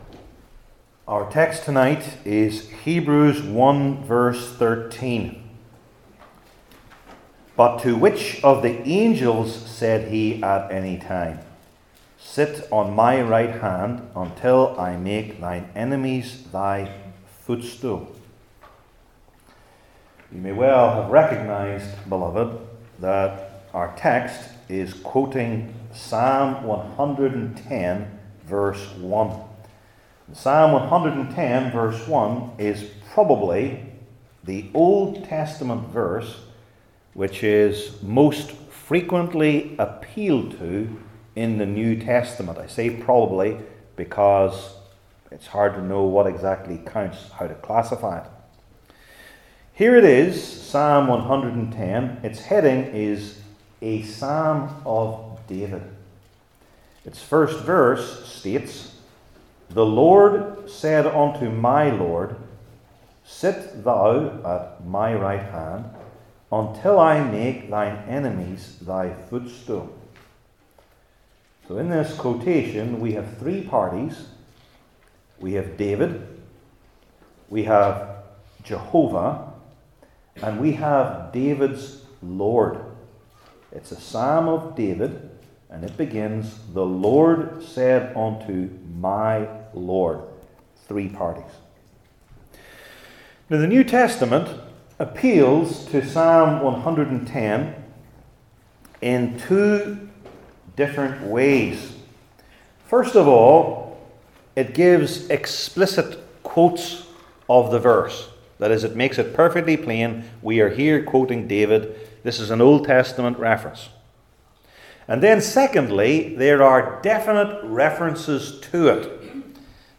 Hebrews 1:13 Service Type: New Testament Sermon Series I. The Glorious Honour of the Son’s Reign II.